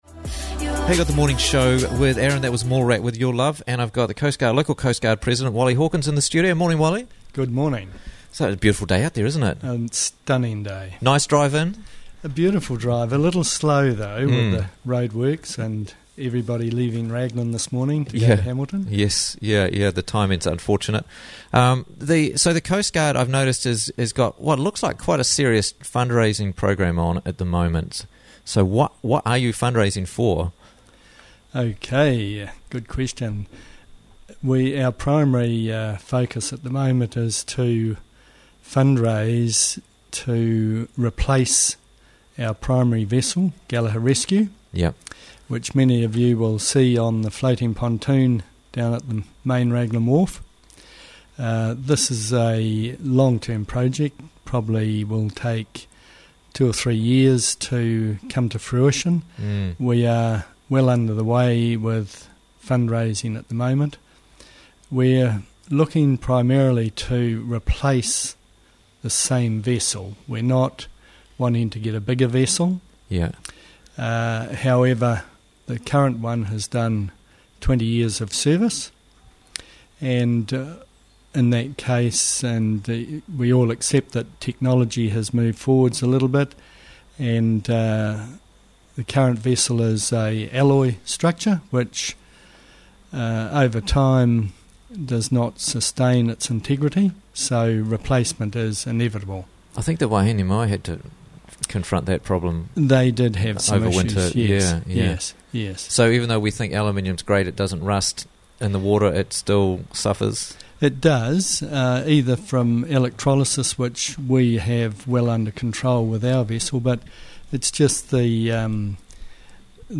Coastguard Fundraising for New Vessel - Interviews from the Raglan Morning Show
in studio